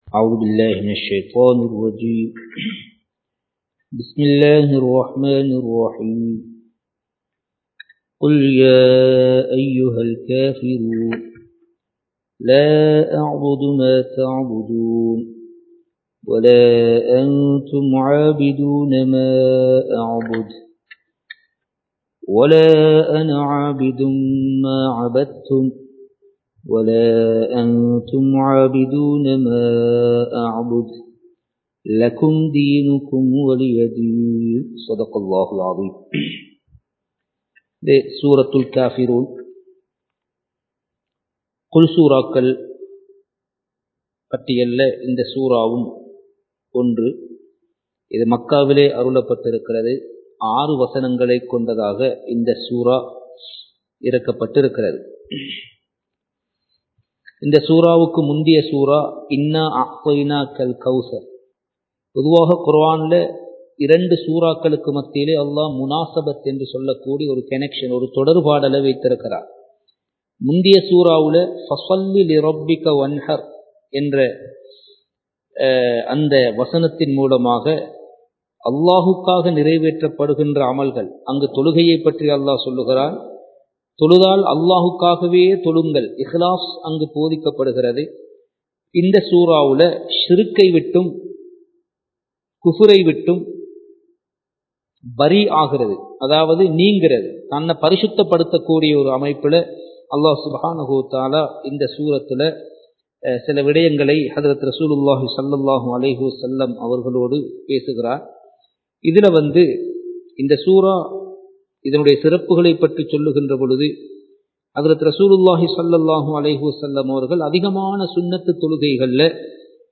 Surah Al Kafirun (Thafseer Class 21) | Audio Bayans | All Ceylon Muslim Youth Community | Addalaichenai